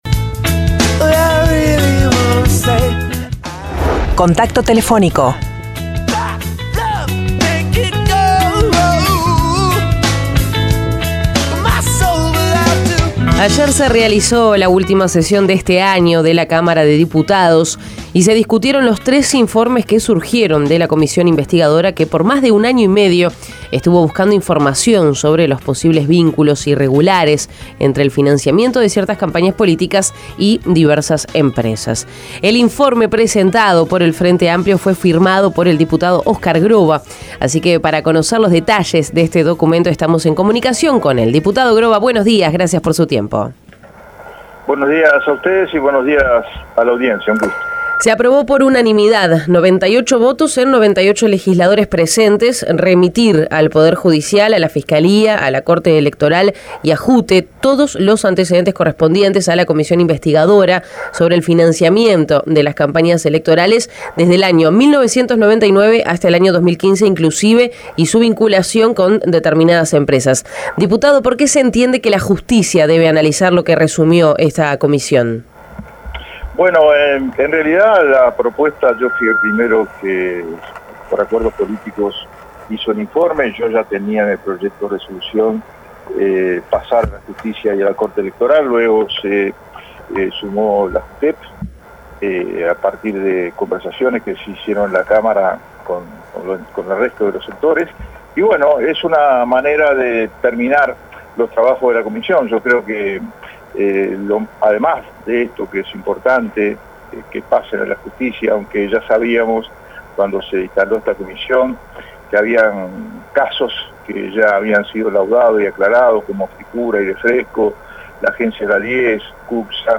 El informe presentado por el oficialismo fue firmado por el diputado Óscar Groba, quien dijo a la Mañana de El Espectador que pasar los trabajos de la comisión a la Justicia es una forma darle cierre a las investigaciones, aunque muchos de los casos ya fueron laudados por el Poder Judicial.